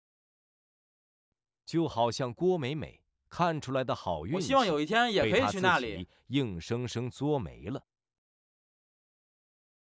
happy_speech.wav